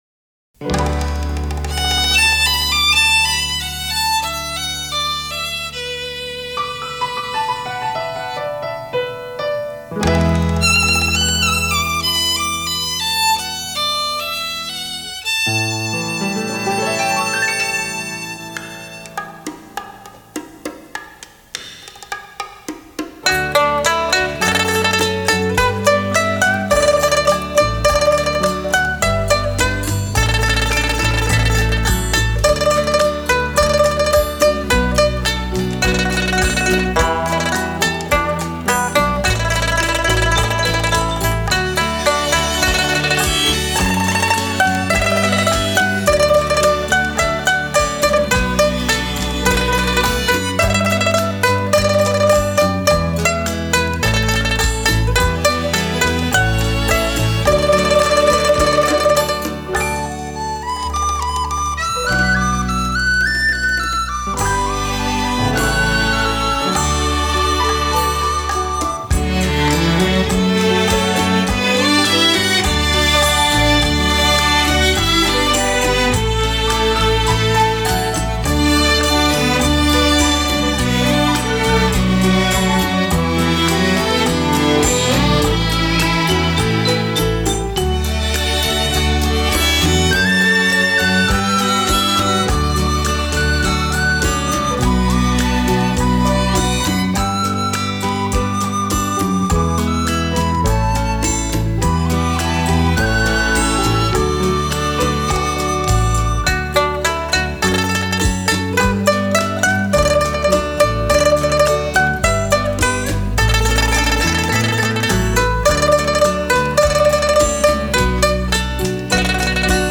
湖面微风 雨后风景